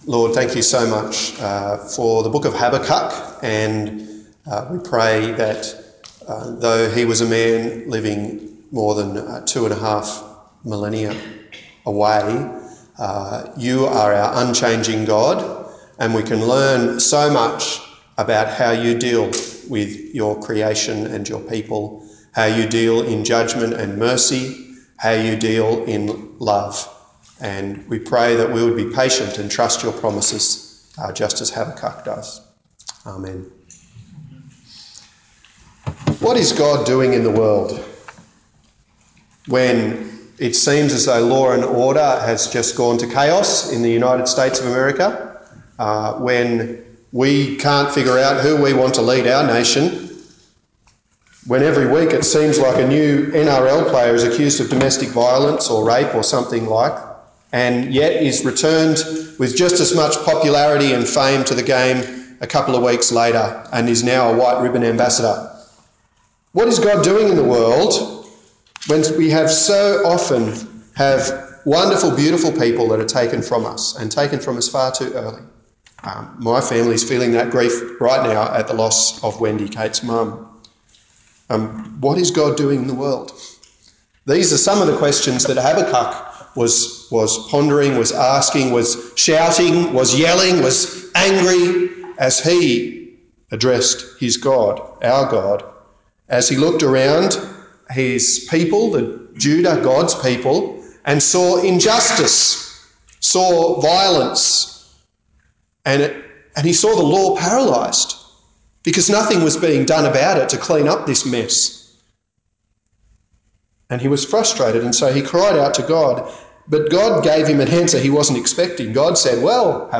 10/07/2016 The Righteous will Live by Faith Preacher